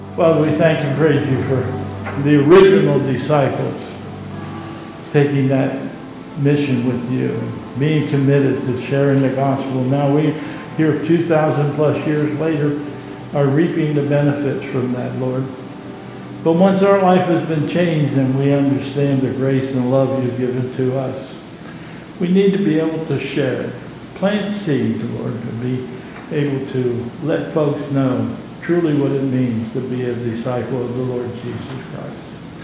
2022 Bethel Covid Time Service
Benediction